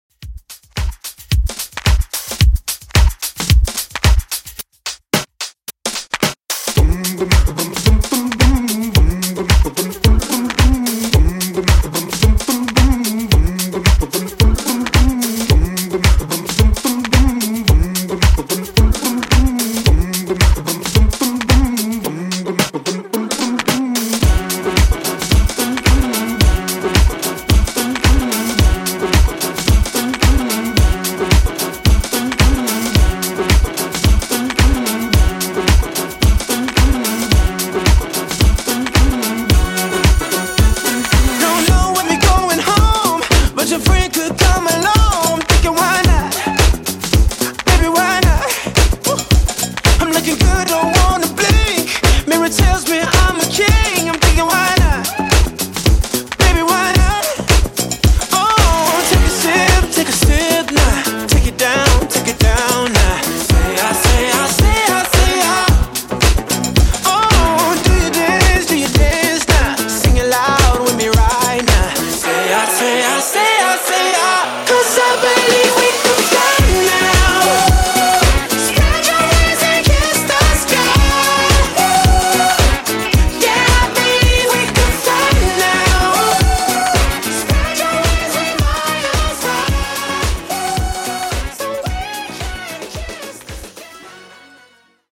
Funky Redrum)Date Added